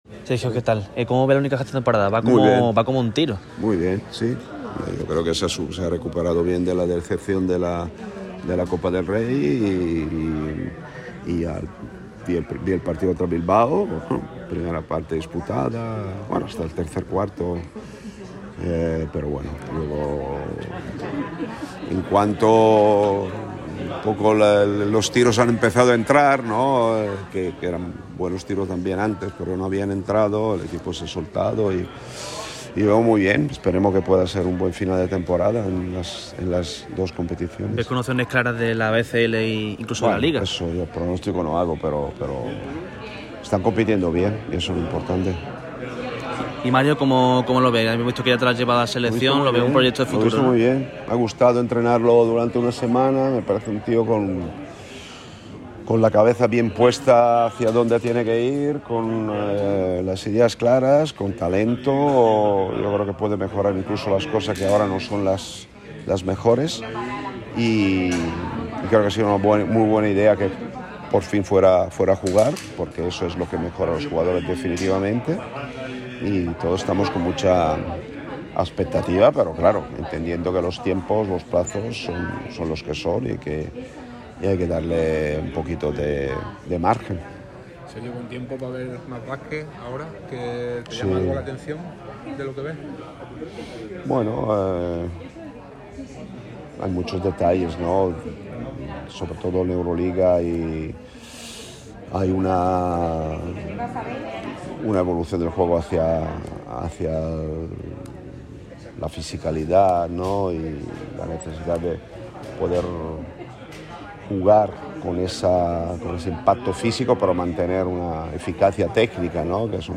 Los cajistas, Alberto Díaz y Yankuba Sima, ganan enteros con Sergio Scariolo. El seleccionador español habló para el micrófono rojo y pasó revista de la situación actual del Unicaja y de sus internacionales españoles. También comentó la buena decisión de Mario Saint Supéry (cedido en Tizona Burgos) de marcharse a LEB Oro para seguir formándose.